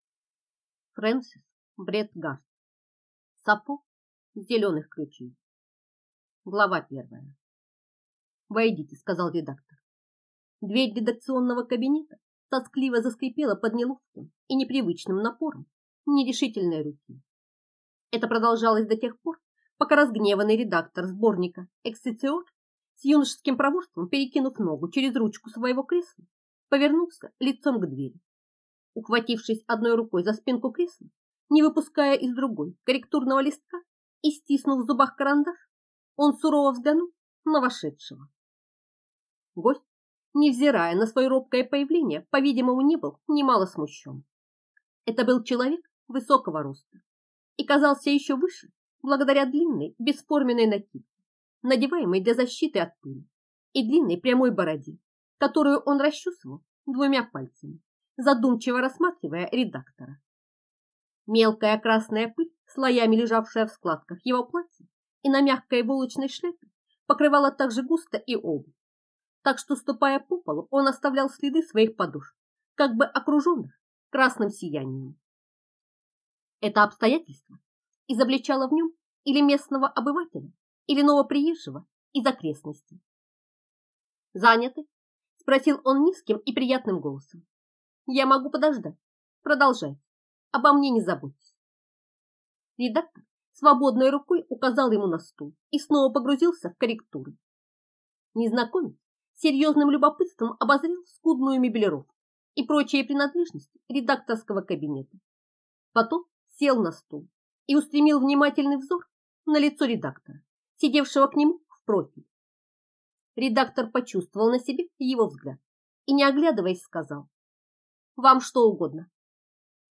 Аудиокнига Сафо с Зеленых Ключей | Библиотека аудиокниг